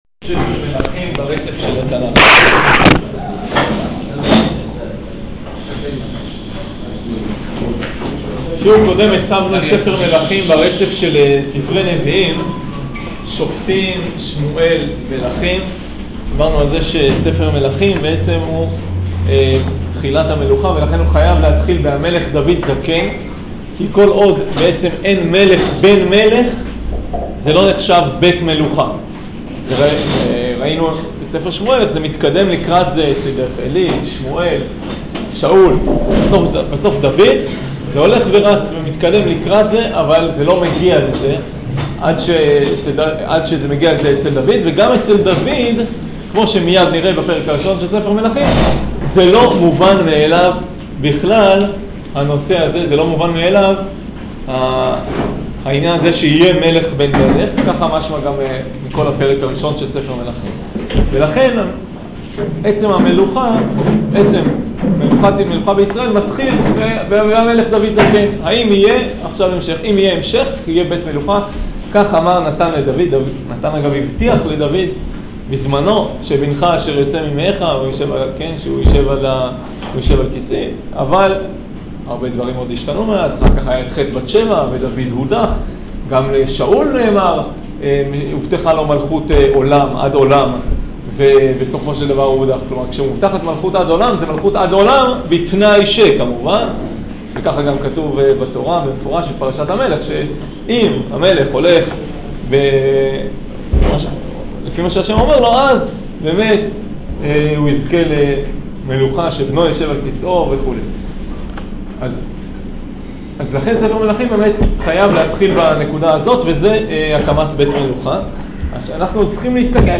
שיעור פרק א